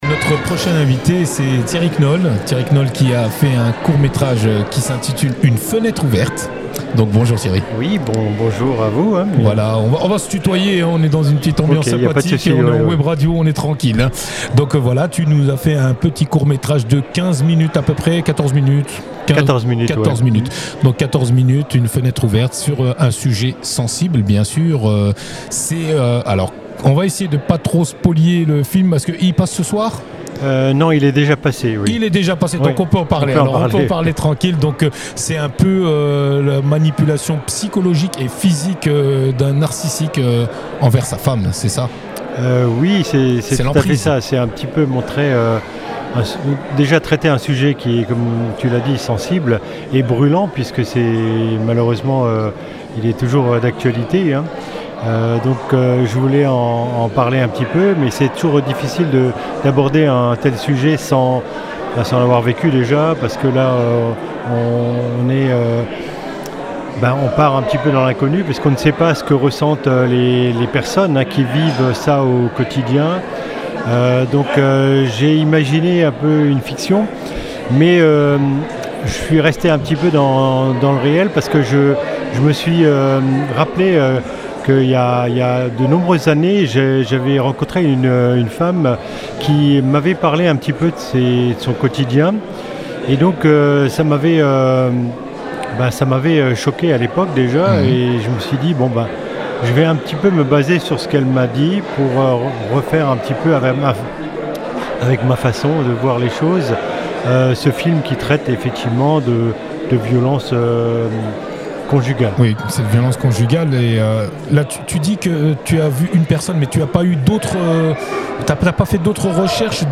Boîte à images (Interviews 2025)